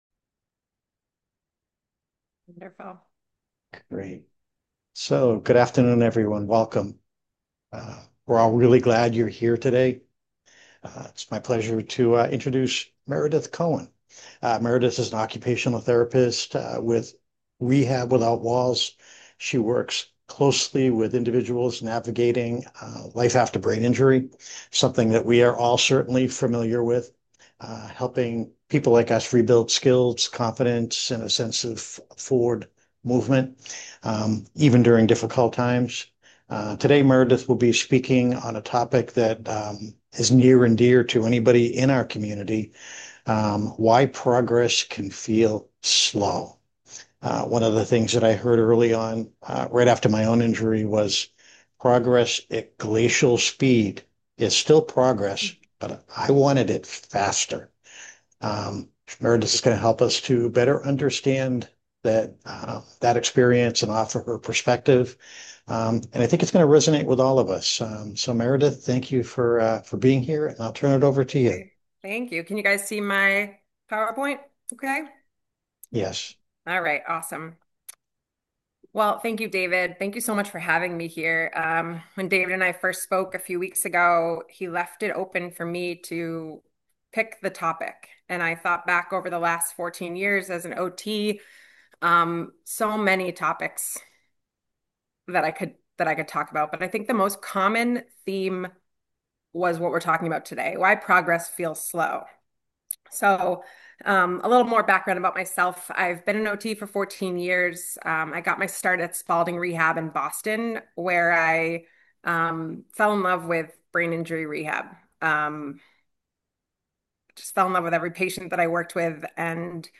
Designed for survivors, caregivers, and clinicians, the session included real-world strategies, space for shared experiences, and a live Q&A following the presentation.